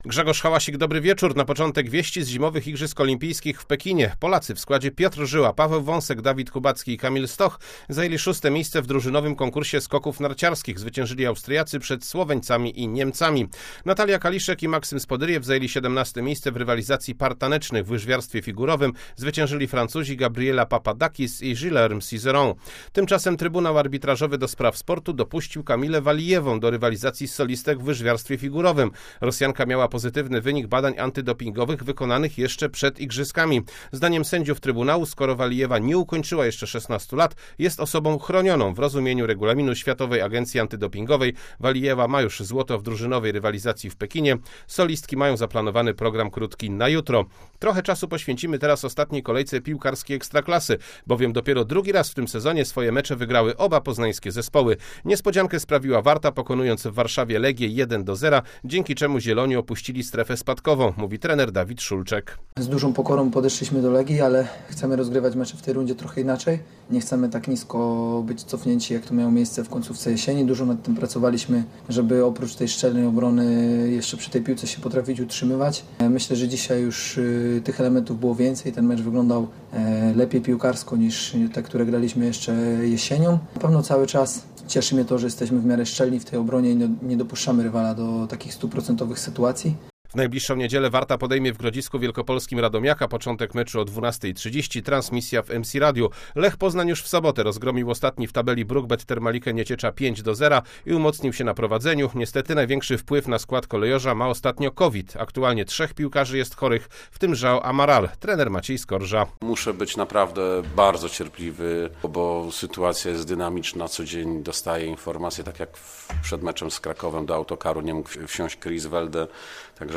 14.02.2022 SERWIS SPORTOWY GODZ. 19:05